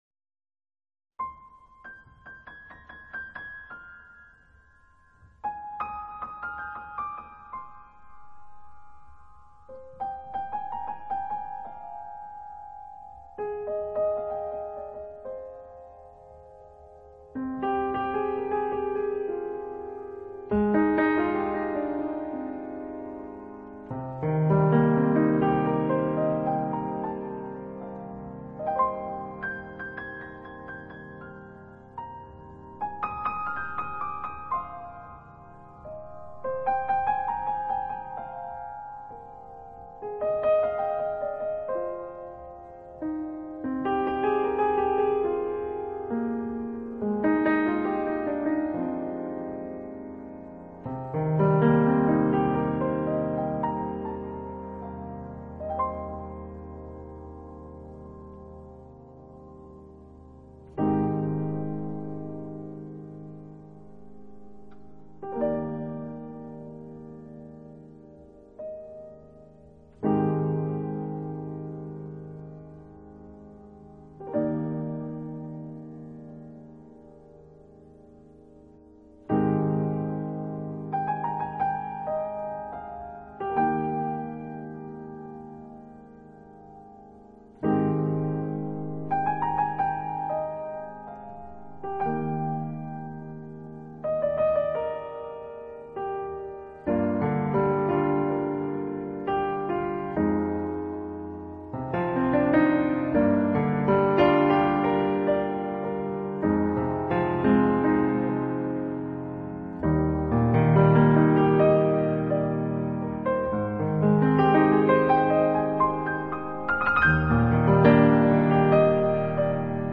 音乐类型：钢琴